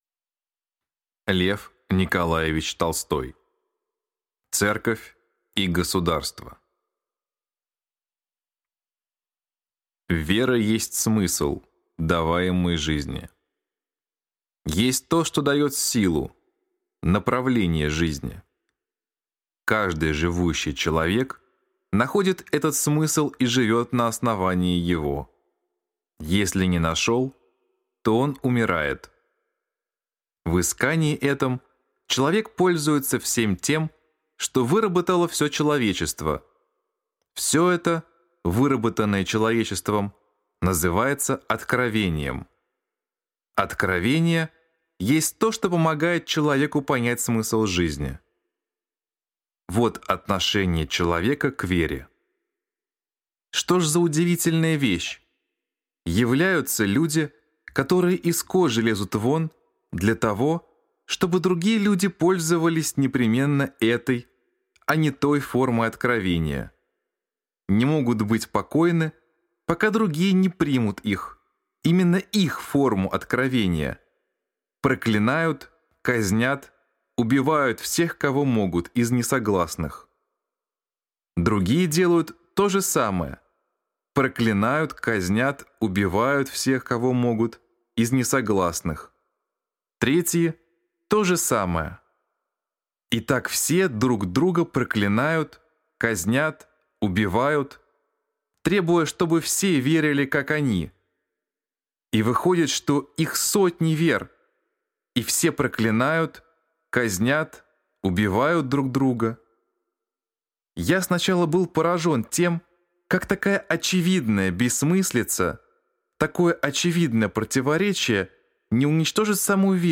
Аудиокнига Церковь и государство | Библиотека аудиокниг